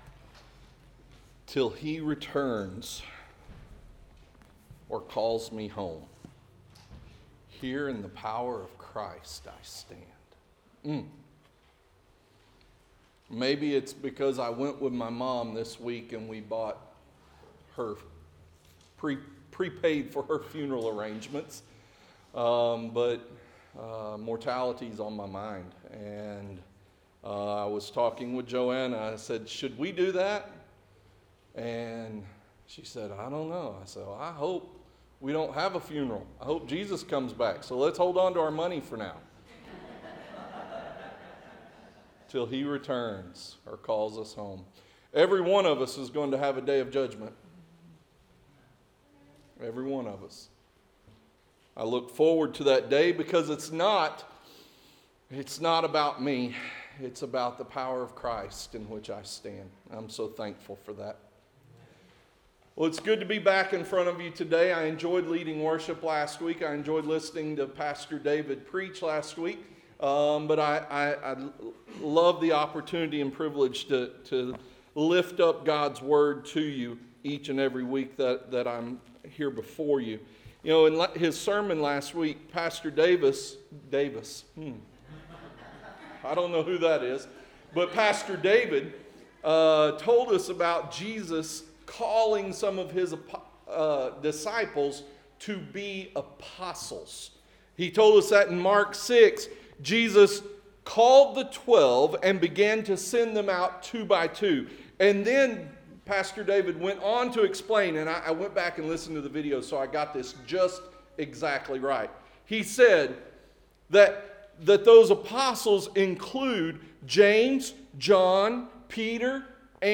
Interactive Sermon Notes Recharge Volume 3 – Full PDF Download Recharge Volume 3 – Week 4 Download Series: LUKE - The Starting Point of Christ's Ministry , LUKE-In the Steps of the Savior